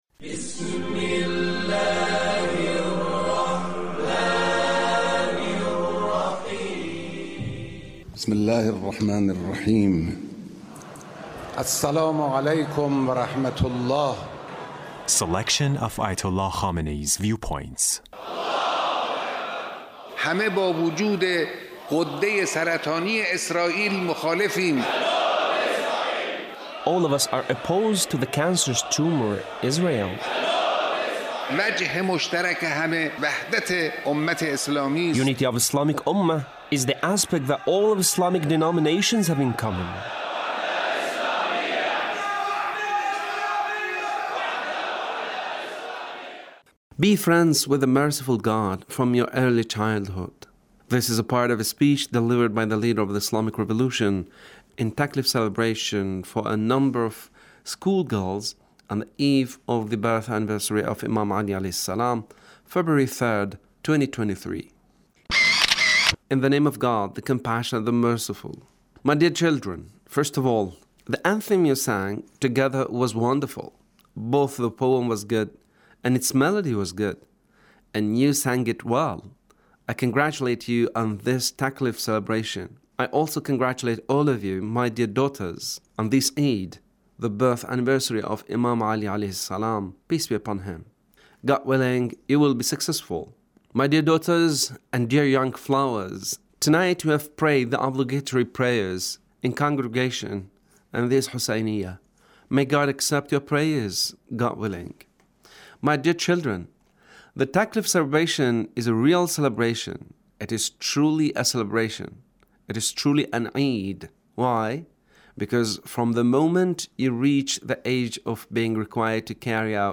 Leader's Speech (1642)
Leader's Speech Taklif Celebration